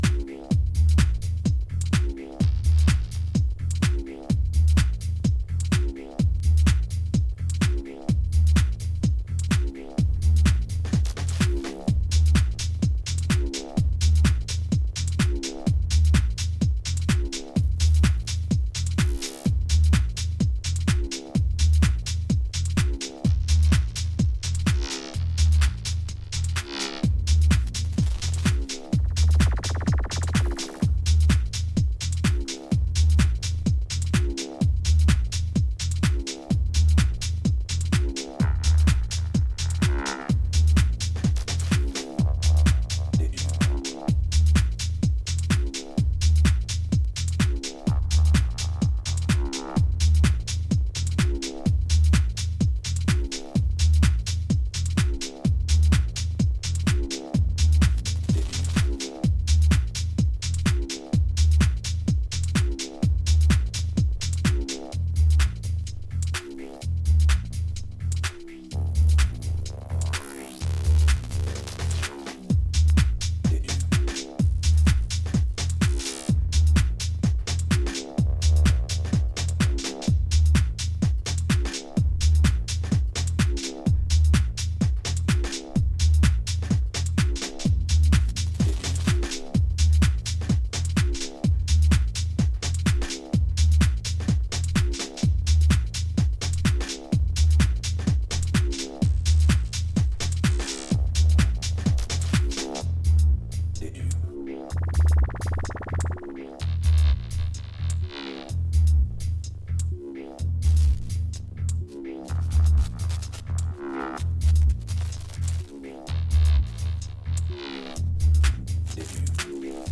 いずれの楽曲も、巧妙なインテリジェンスを感じさせるテクスチャーが構築されたアブストラクトなミニマル・ハウスといった趣。